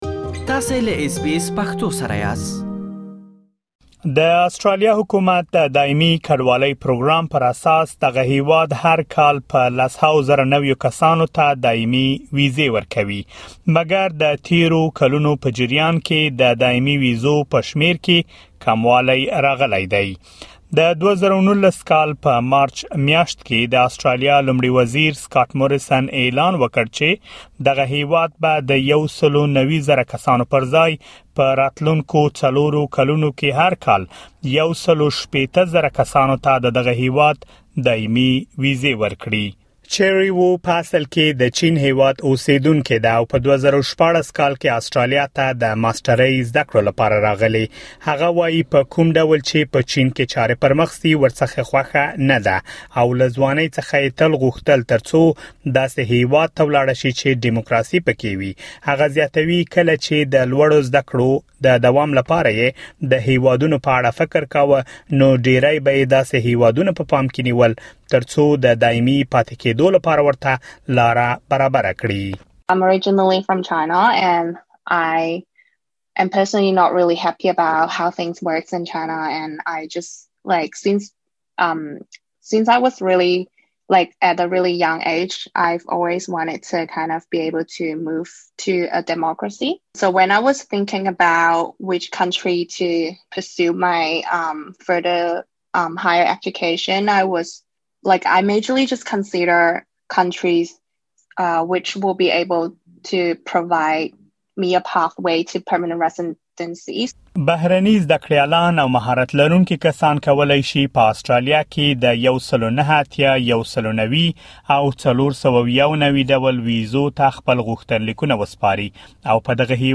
د اسټراليا مهاجرت پروګرام له مخه هر کال ۱۶۰ زره مهاجر اسټراليا ته راوستل کيږي ترڅو اسټراليا کې کاري تشې ډکې کړي. يادو شميرو څخه ډير ځايونه مهارت لرونکو مهاجرو ته ورکول کيږي. د اسټراليا دايمې ويزې ترلاسه کولو لپاره پروسه ډيره رقابتي ده ولې پدې رپوټ کې به تاسې د اسټراليا مهاجرت پروګرام په اړه معلومات ترلاسه کړئ.